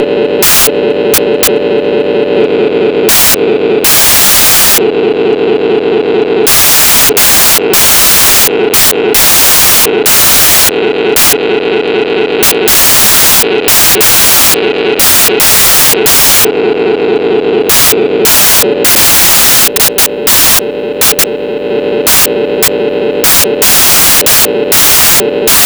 The ADSMK2 is an auto diesel train sound board that has been designed to mimic diesel engines it is a non dcc circuit board and has the advantage of being able to be adjusted to sound like different engines with just one adjustment. you can make it sound from a whining supercharged diesel to a ruff old growler.
SOUND SAMPLE 2 MOVING ADJUSTER 2 FROM WHINE TO
RATTLE
WHINE_TO_RATTLE.wav